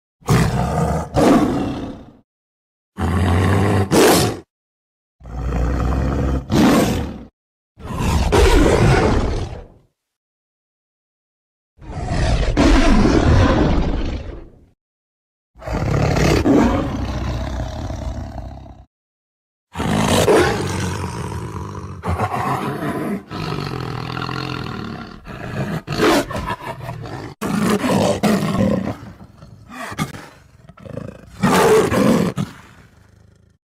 Tiger Lion Werewolf And Leopard Sounds Bouton sonore